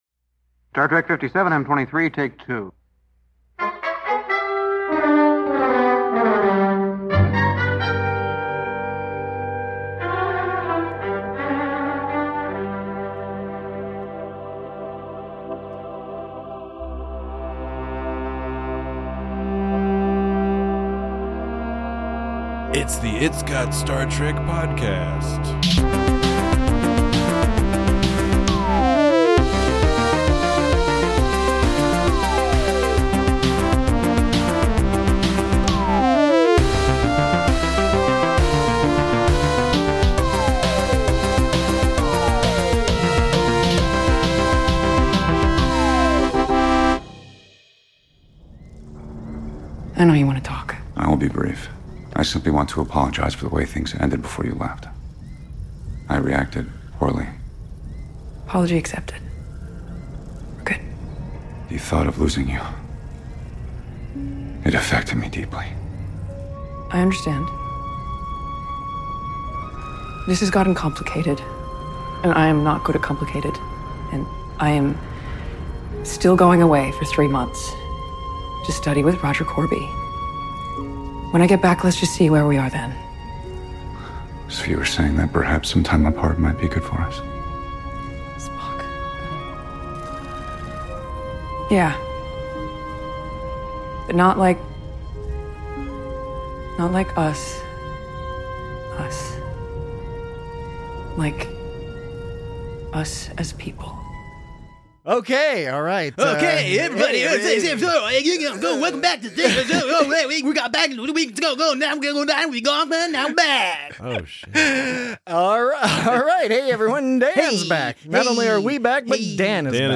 La'an battles gremliny Gorn while Spock battles gremliny feelings. Join your entertained but confused hosts as they discuss Part II-ishness (or Part II-itis?) in Star Trek, the welcome return of such wonderful characters and actors, and the cinematic and restrained direction of this season opener.